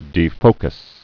(dē-fōkəs)